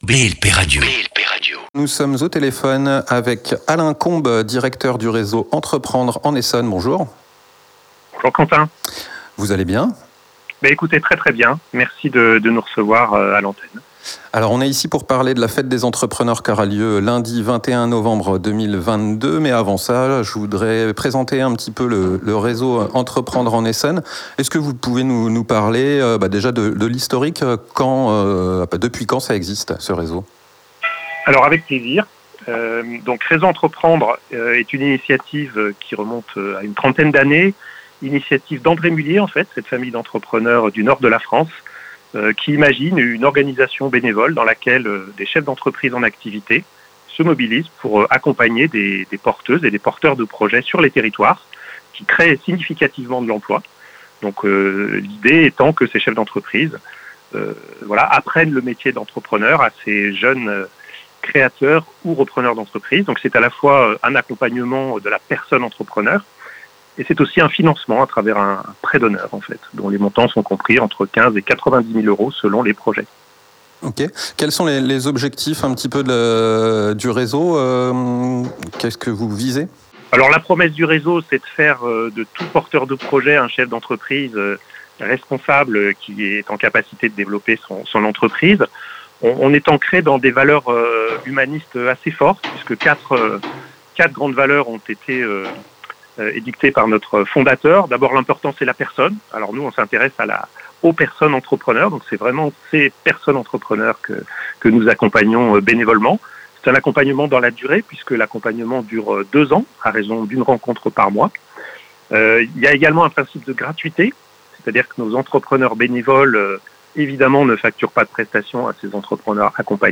ITV Reseau Entreprendre.mp3